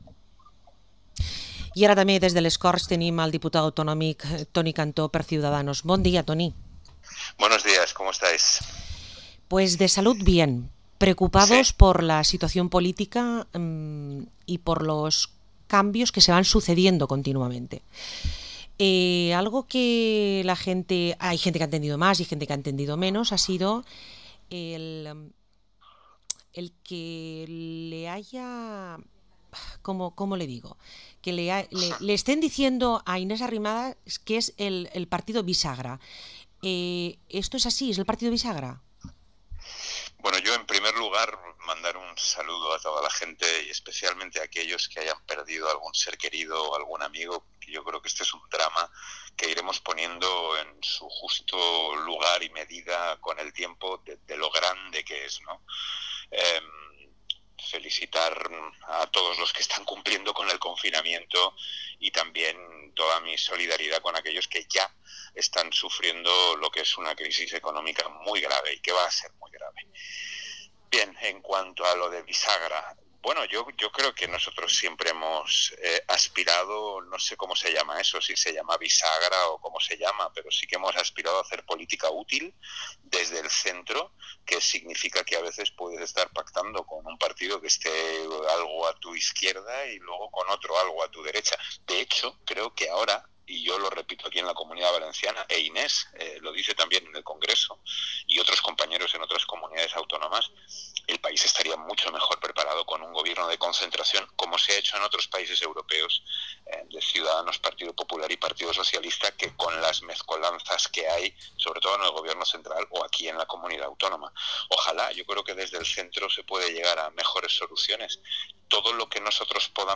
Entrevista al diputado autonomico y líder de Ciudadanos, Toni Cantó